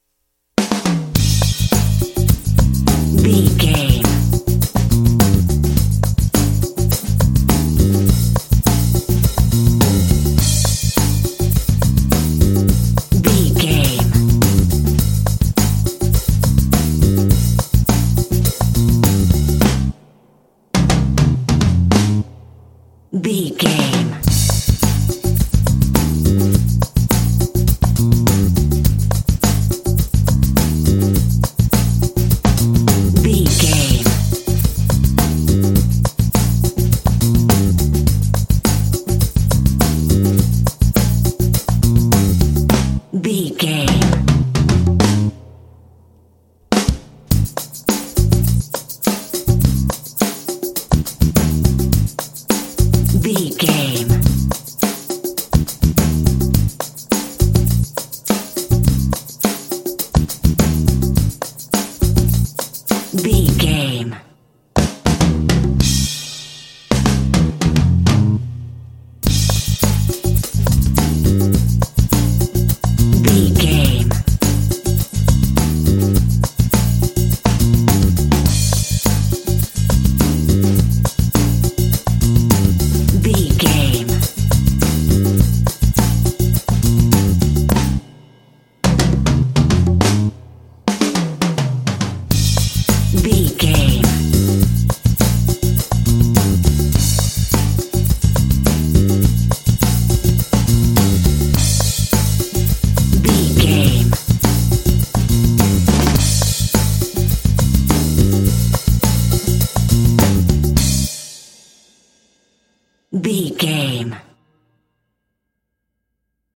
Uplifting
Aeolian/Minor
groovy
driving
energetic
bass guitar
drums